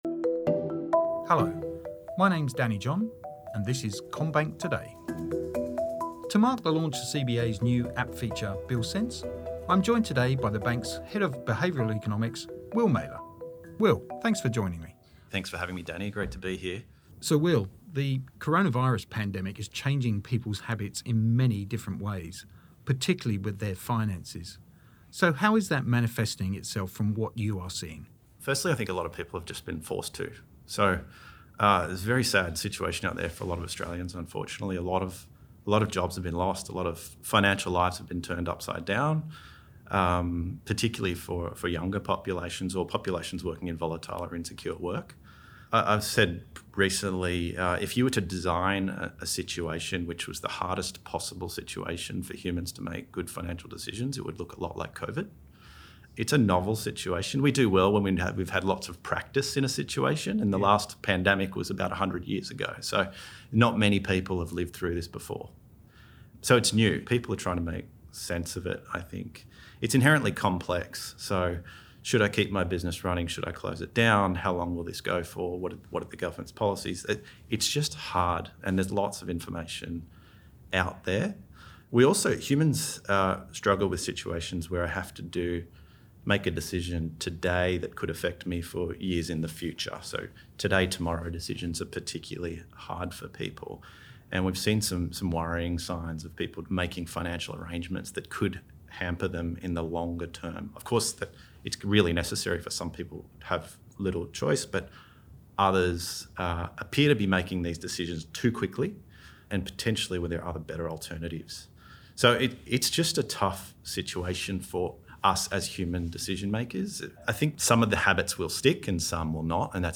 In a new podcast interview